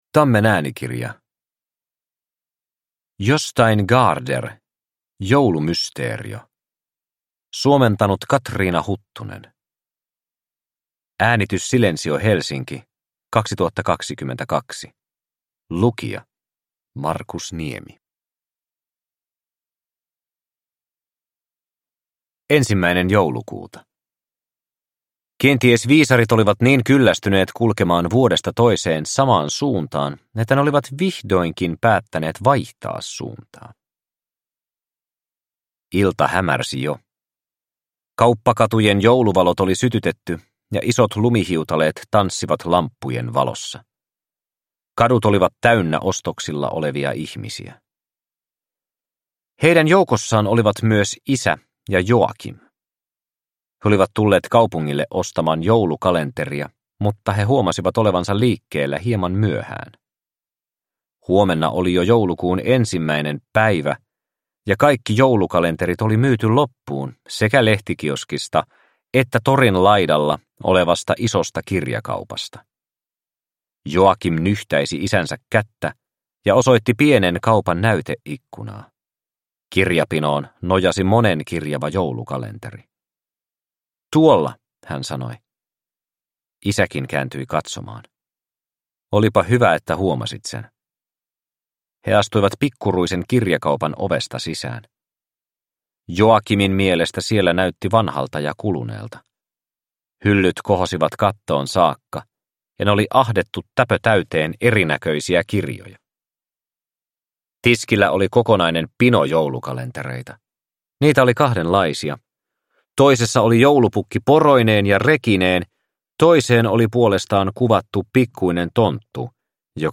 Joulumysteerio – Ljudbok – Laddas ner